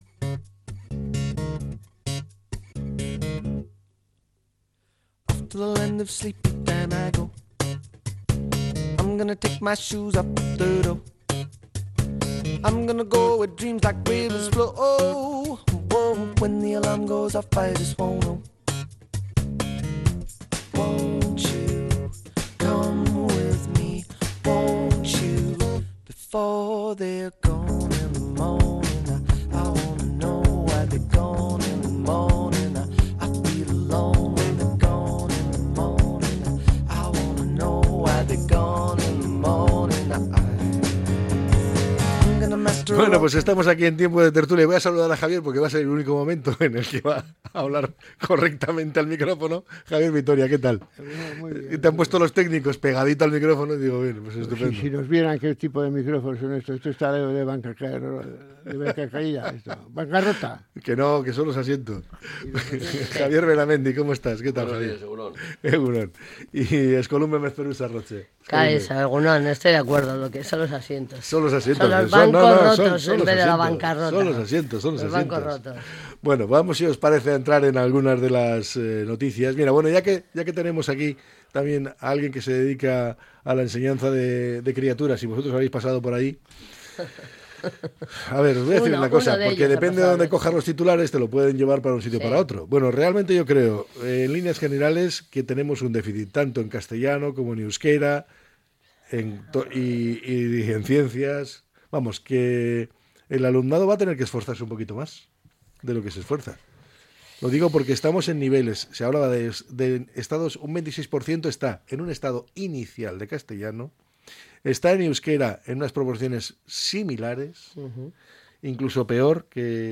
La tertulia 18-02-25.